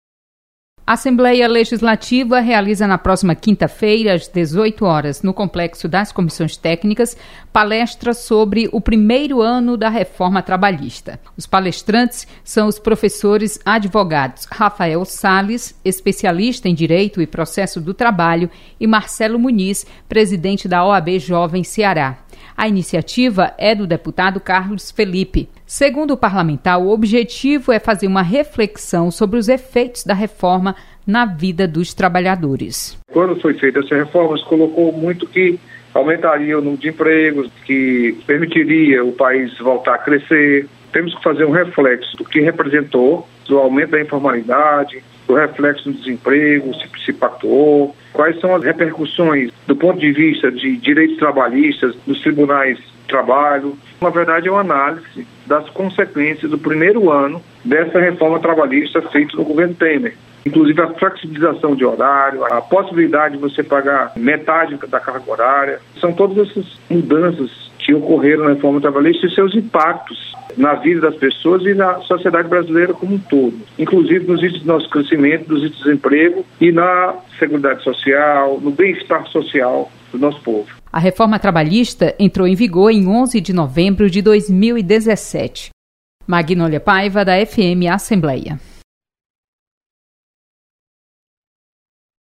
Você está aqui: Início Comunicação Rádio FM Assembleia Notícias Palestra